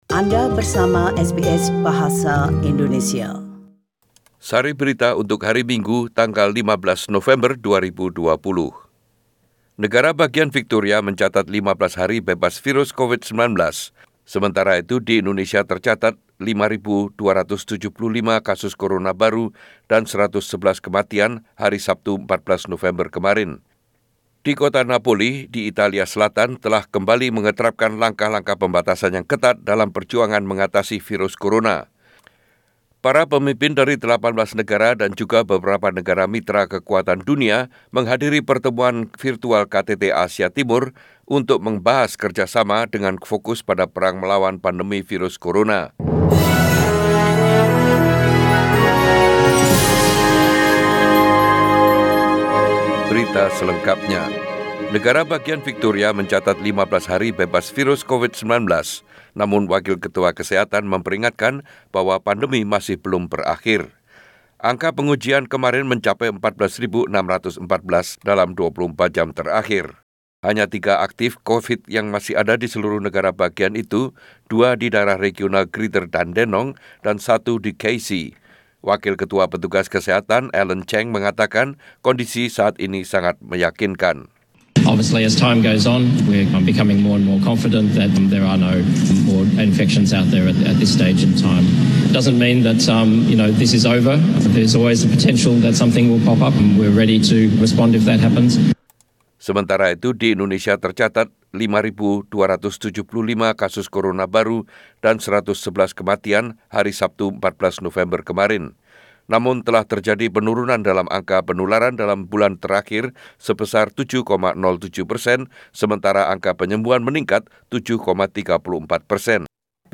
Warta Berita Radio SBS Program Bahasa Indonesia - 15 November 2020